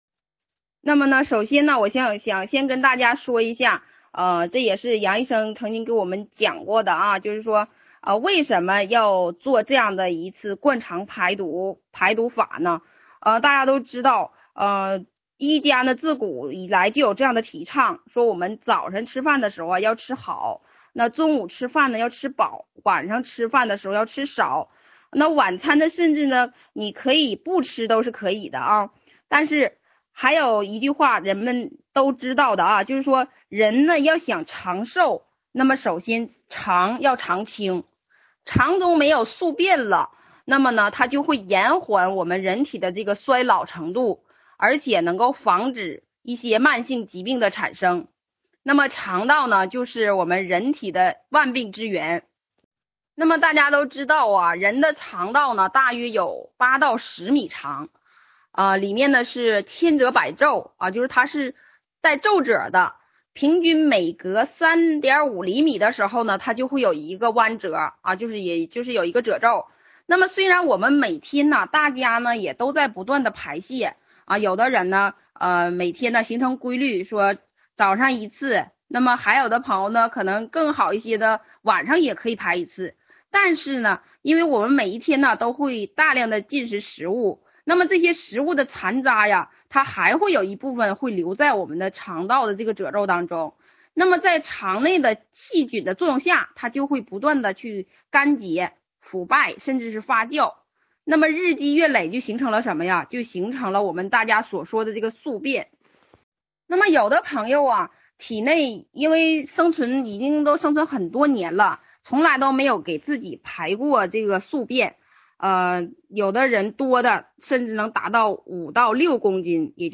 【音频-灌肠详细讲解】：